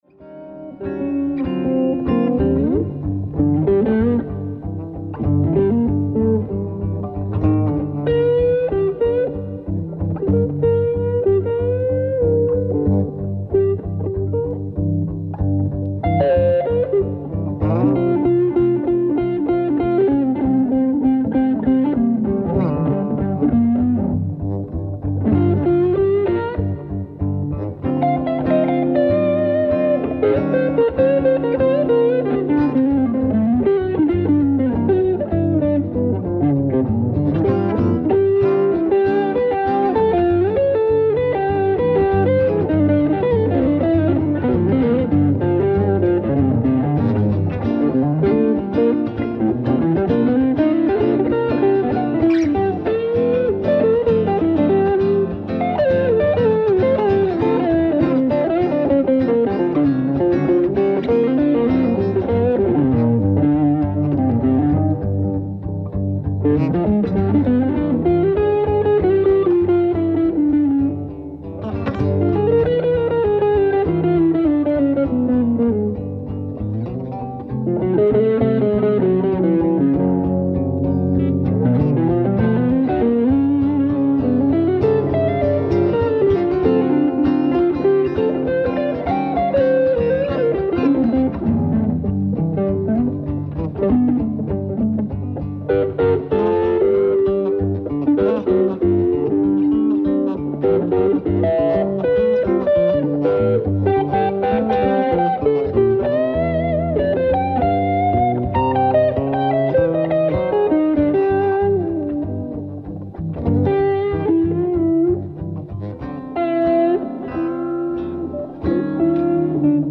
ライブ・アット・フルブール、スイス 12/01/2007
※試聴用に実際より音質を落としています。